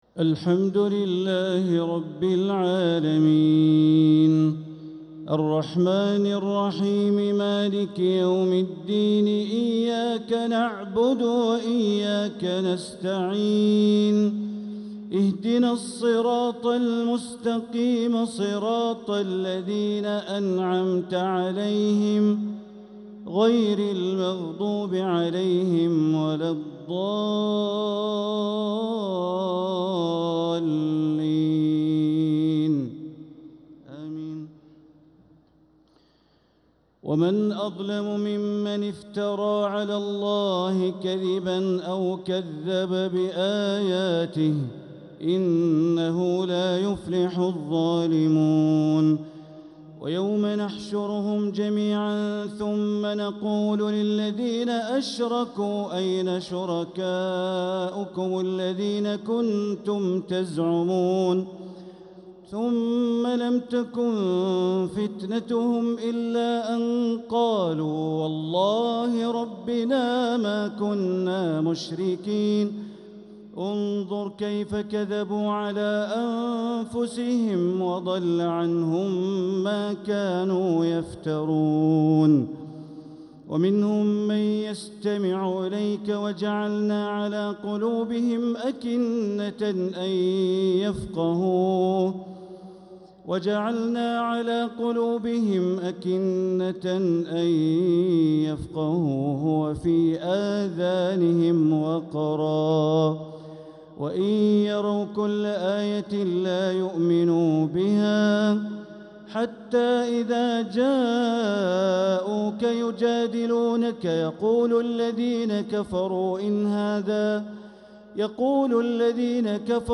taraweeh 9th niqht ramadan 1446H Surat Al-Anaam > Taraweeh Ramadan 1446H > Taraweeh - Bandar Baleela Recitations